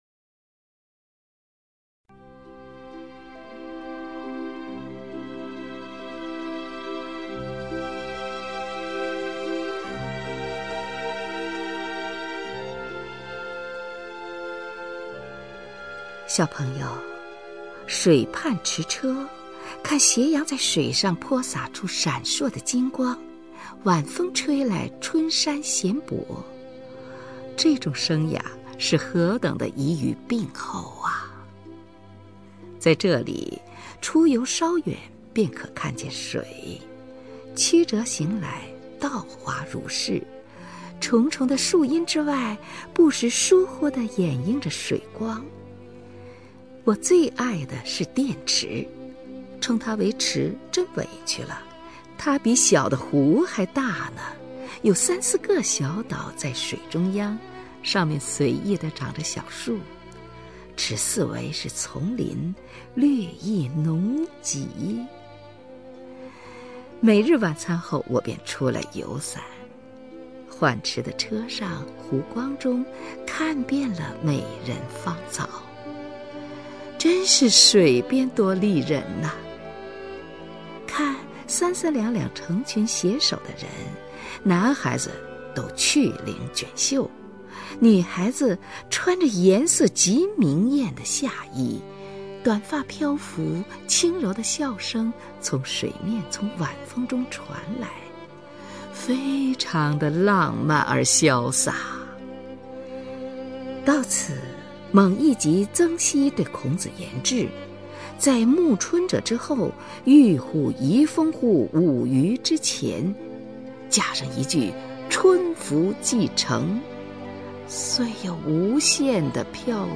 虹云朗诵：《寄小读者（通讯二十）》(冰心) 冰心 名家朗诵欣赏虹云 语文PLUS